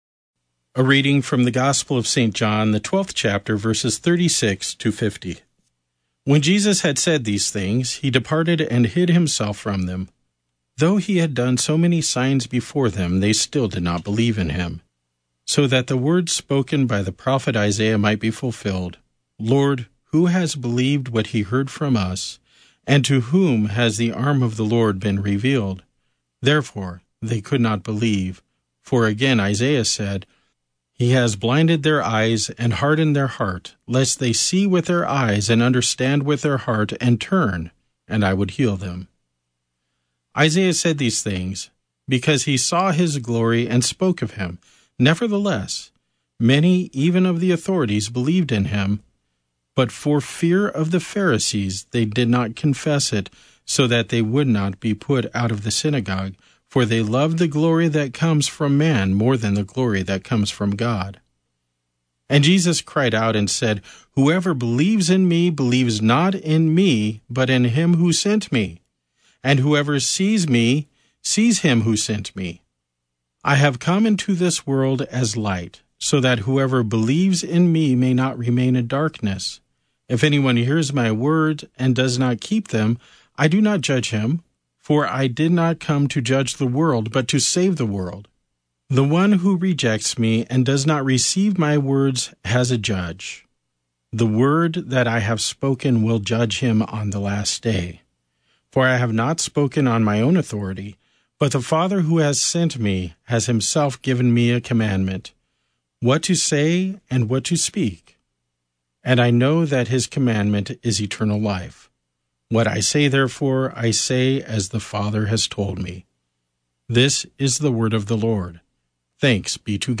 gives today's sermonette.